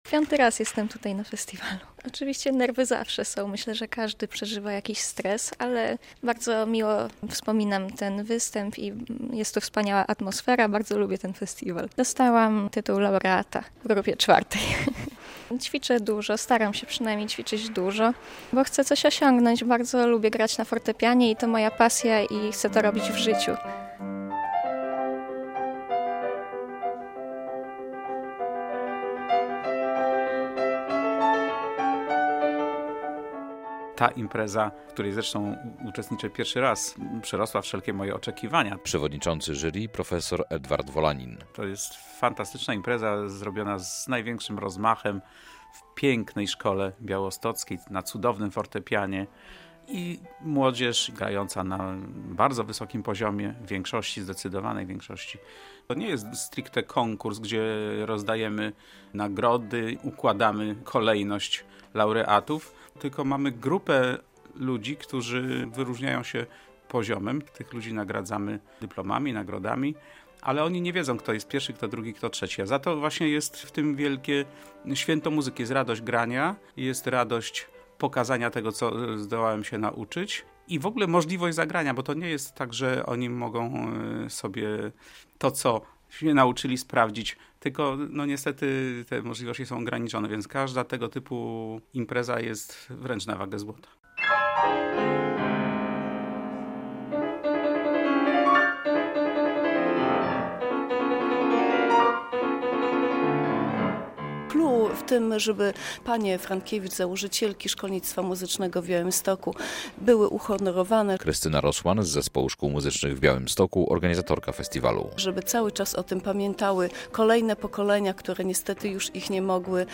100 uczniów prezentowało się w białostockim Zespole Szkół Muzycznych podczas V Festiwalu im. sióstr Frankiewicz.
relacja